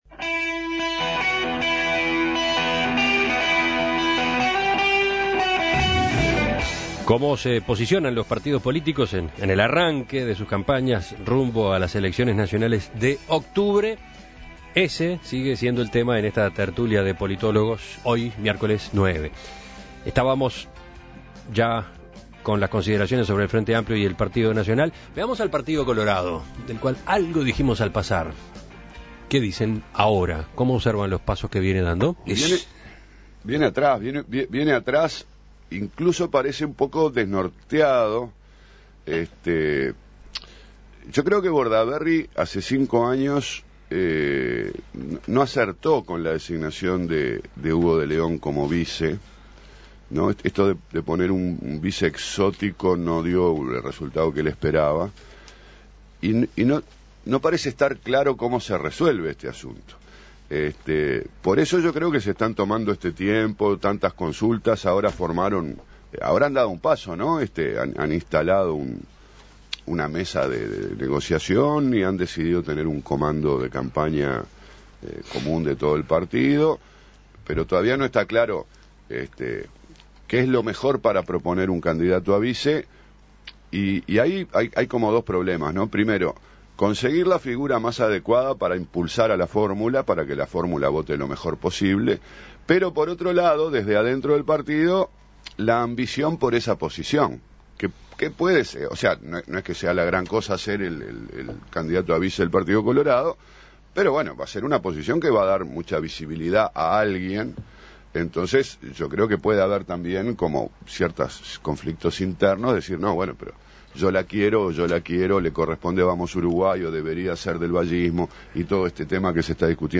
LA TERTULIA Tertulia de Politólogos: ¿Cómo se posiciona el Partido Colorado de cara a las elecciones nacionales?